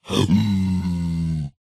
zpigangry4.ogg